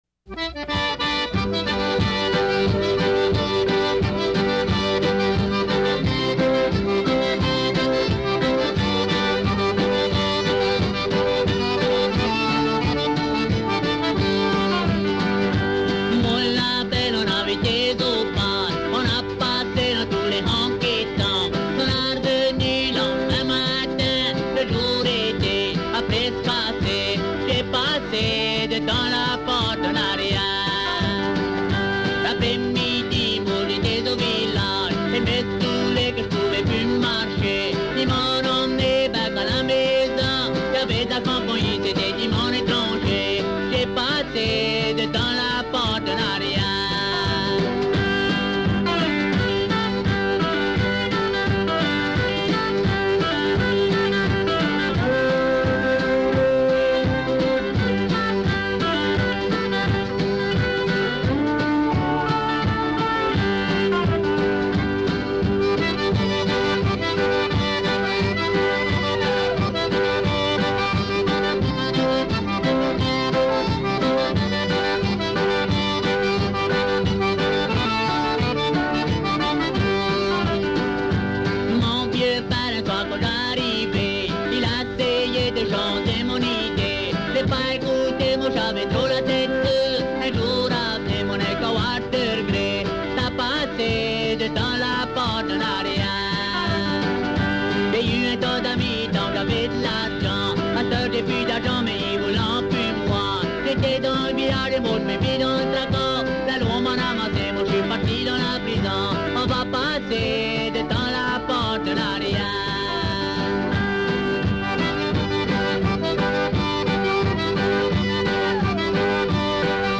Classic 2/4
This lesson’s tune is a two-step, or 2/4 type feel.